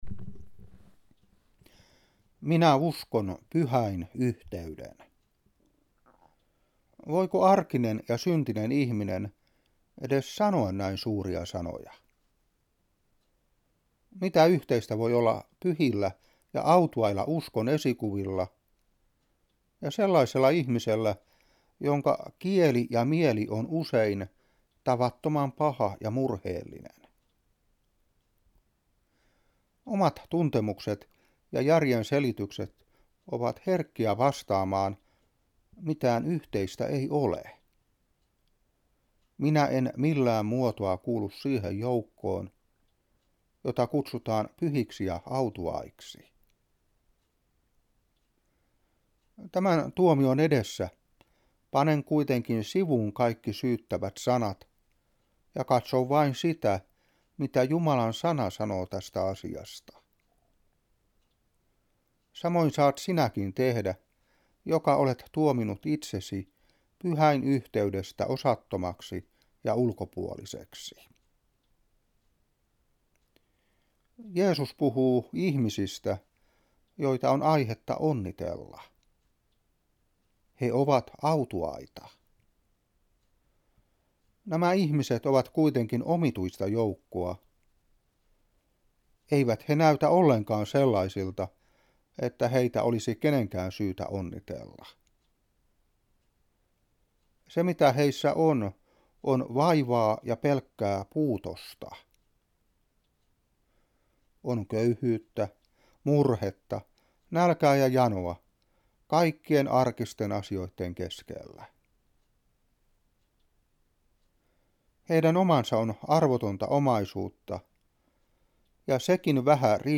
Saarna 2000-11. Matt.5:1-12.